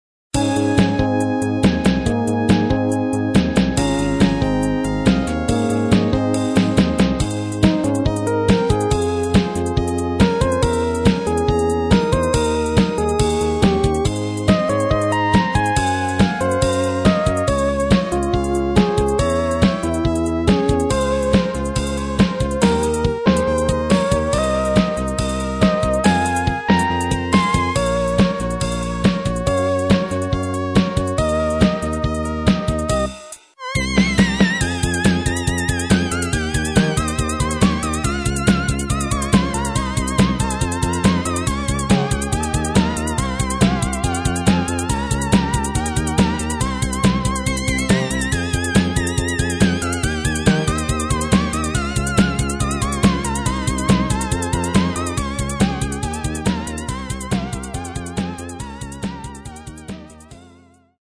PC-88VA2のサウンドボードIIの音源をそのまま録音した曲集です。